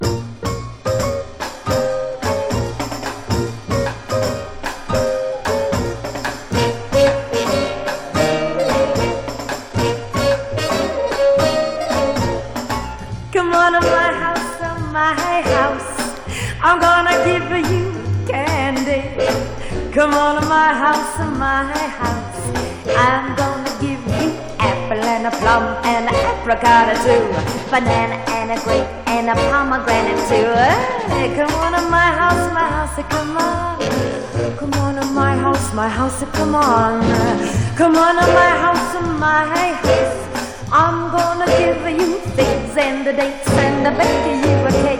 こちらは、ジャズ・ボーカル作品。
表現力が高いボーカルは、飽きのこない伸びやかさ。音もアレンジも妙技と思える最高さで彩り。
Jazz, Pop, Vocal　USA　12inchレコード　33rpm　Mono